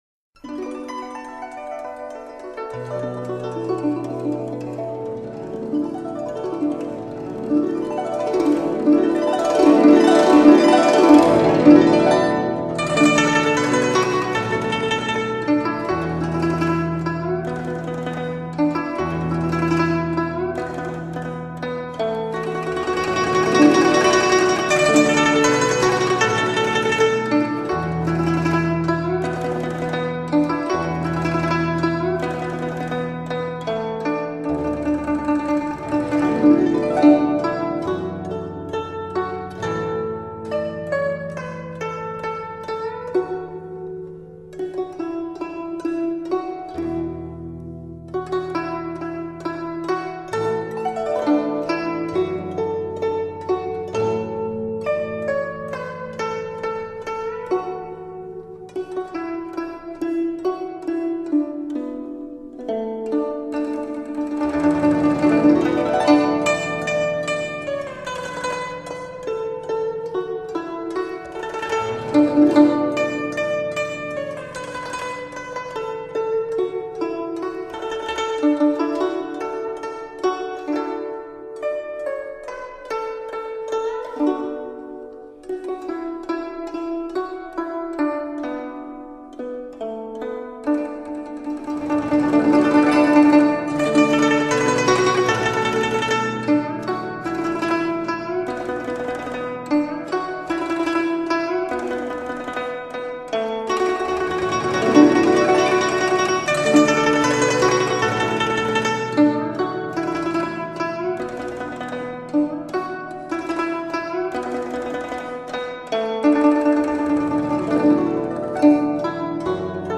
古筝音调清丽温婉，轻快通透。
以古筝全新演绎流行音乐和民族音乐，使东方传统器乐脱胎换骨，给你意想不到的惊喜。